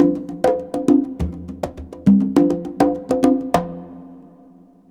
CONGBEAT13-R.wav